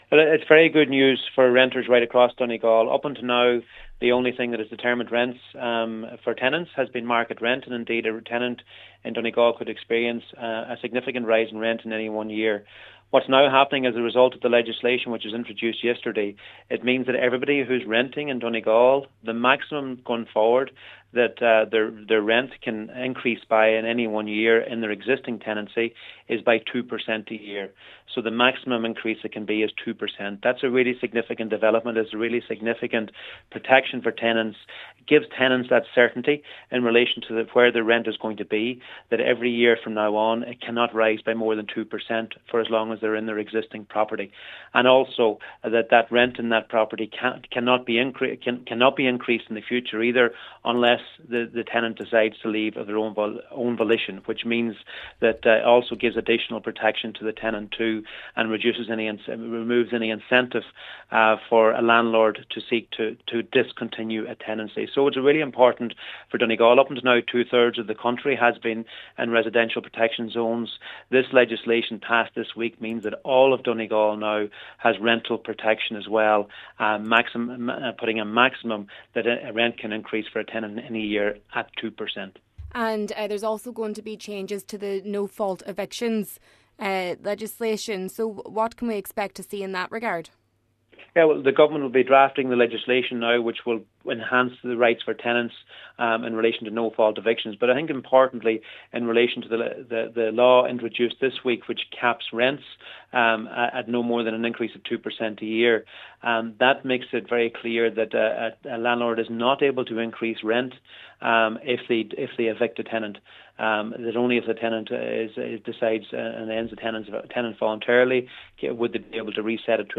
Minister of State at the Department of Arts, Media, and Sport, Charlie McConalogue says it’s good news for people in this county: